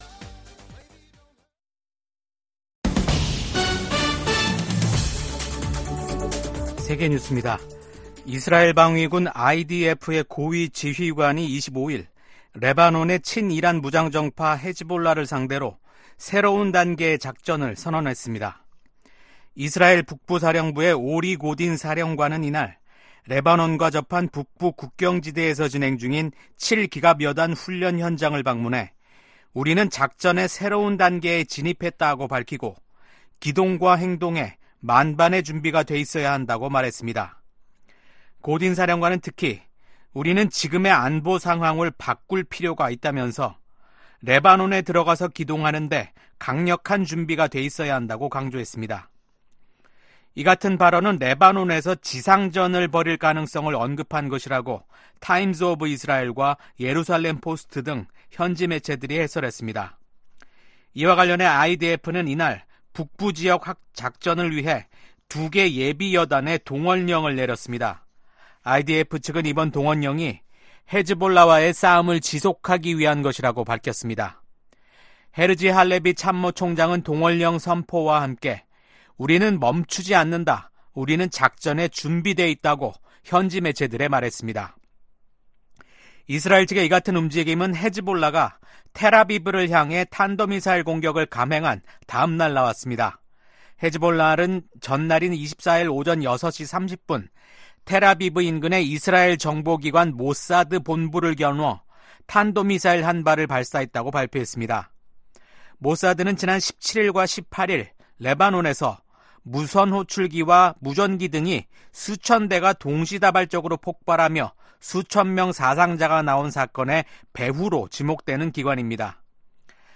VOA 한국어 아침 뉴스 프로그램 '워싱턴 뉴스 광장' 2024년 9월 26일 방송입니다. 조 바이든 미국 대통령이 임기 마지막 유엔총회 연설에서 각국이 단합해 전 세계가 직면한 어려움들을 이겨내야 한다고 밝혔습니다. 토니 블링컨 미국 국무장관은 심화되는 북러 군사협력을 강하게 규탄하며 국제사회의 강력한 대응의 필요성을 역설했습니다.